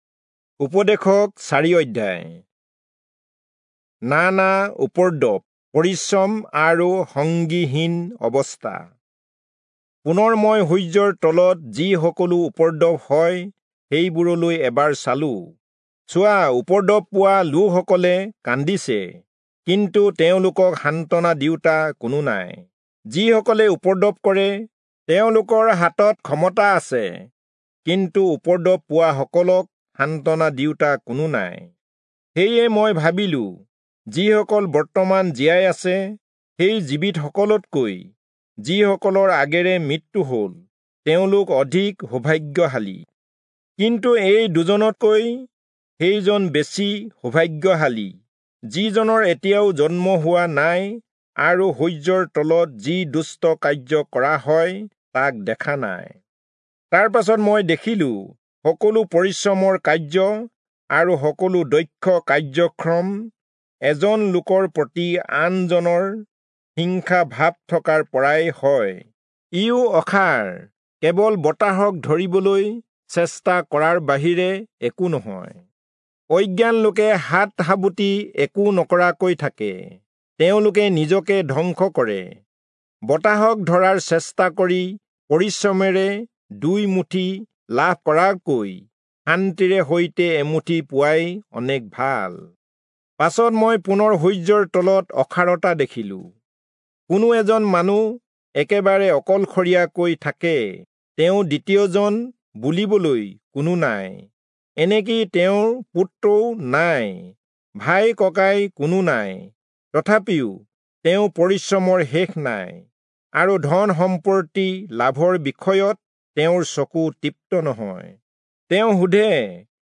Assamese Audio Bible - Ecclesiastes 2 in Gnttrp bible version